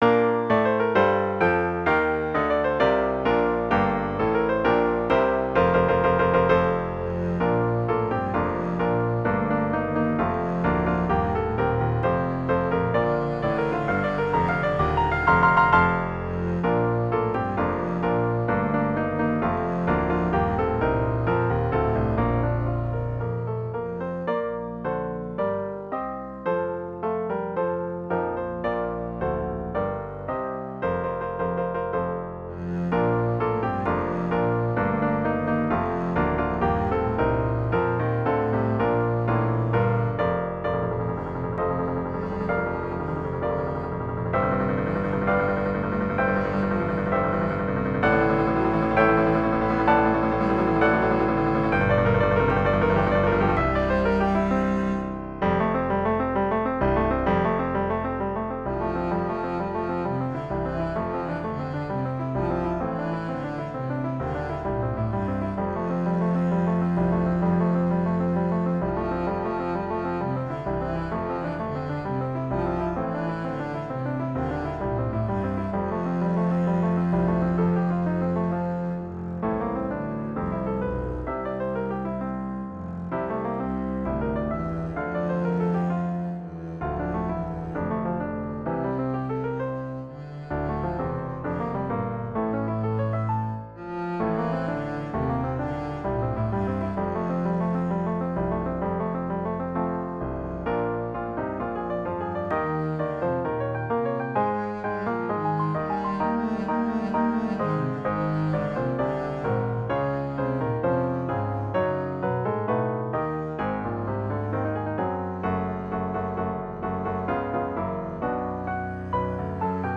Two hymns set to the form of a classical sonata.